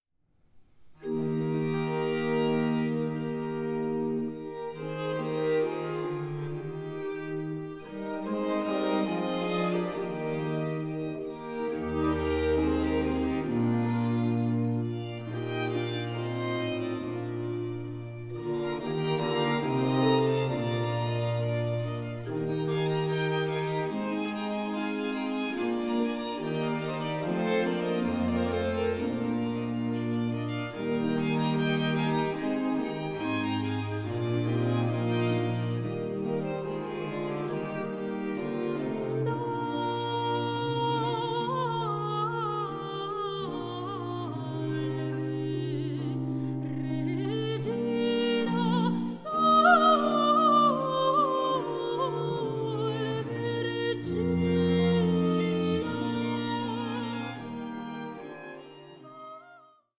HIDDEN GEMS OF DUTCH BAROQUE VOCAL MUSIC